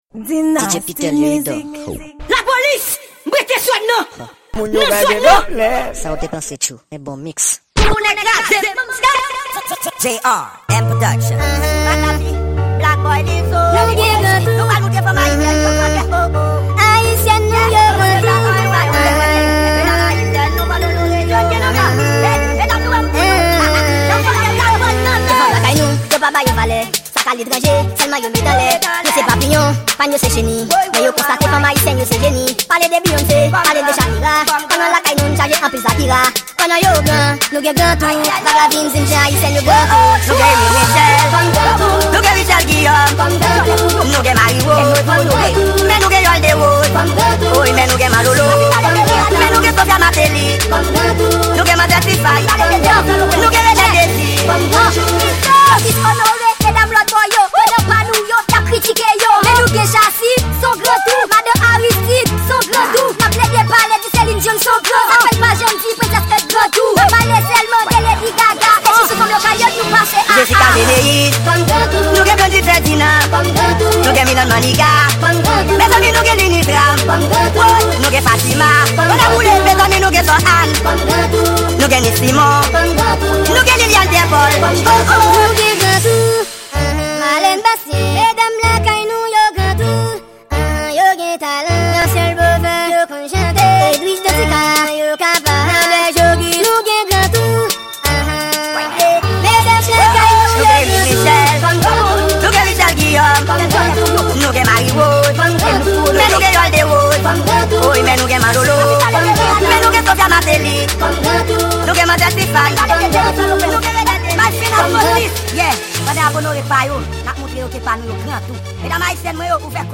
Genre:Mix.